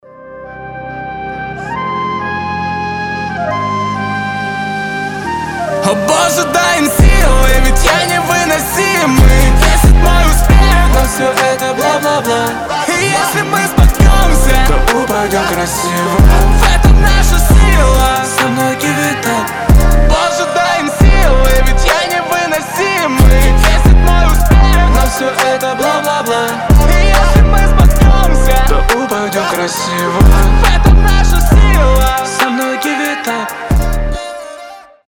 • Качество: 320, Stereo
басы